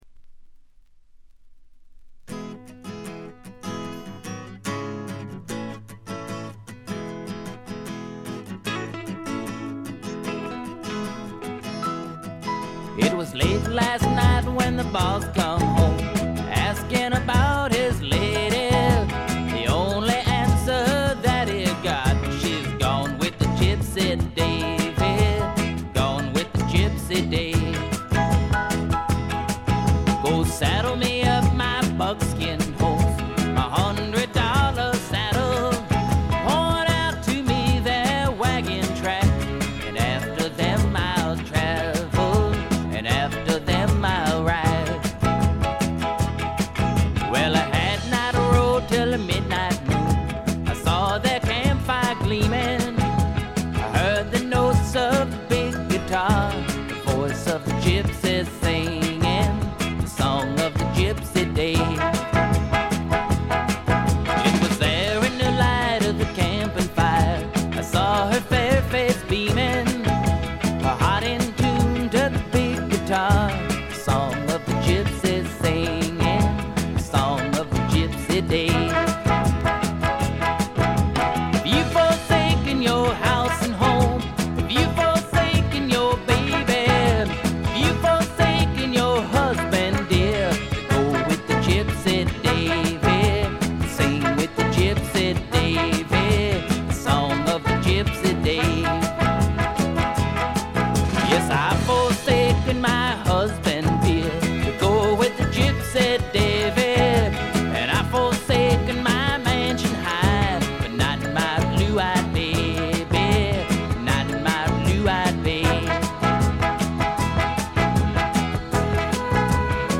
これ以外はほとんどノイズ感無し。
試聴曲は現品からの取り込み音源です。